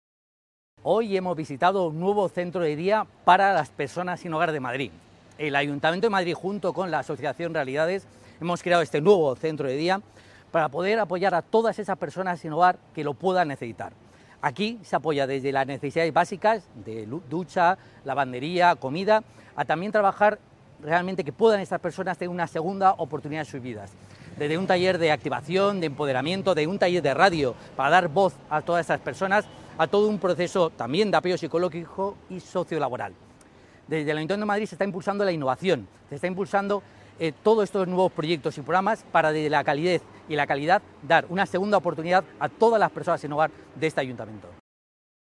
Declaraciones del delegado del Área de Familias, Igualdad y Bienestar Social, Pepe Aniorte, durante su visita esta mañana al nuevo Centro Municipal de Día para Personas Sin Hogar puesto en marcha en el distrito de Usera junto